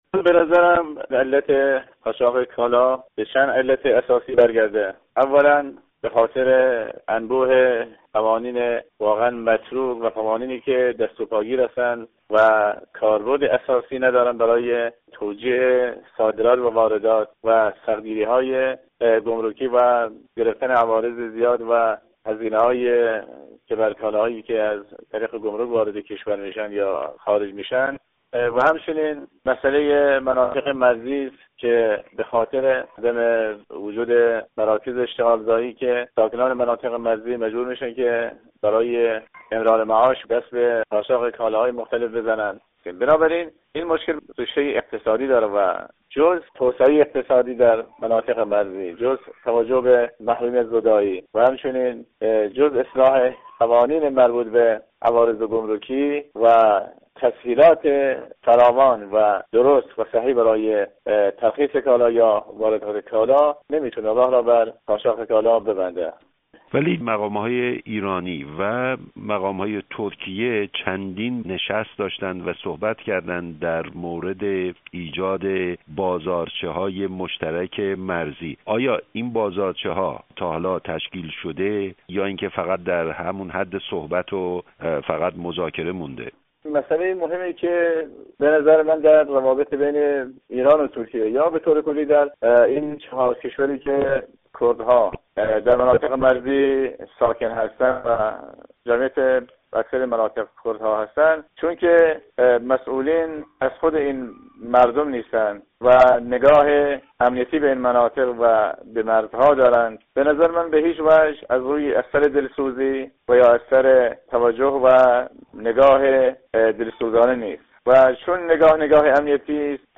گفتگو با جلال جلالی‌زاده در مورد رواج قاچاق در مرزهای غربی ایران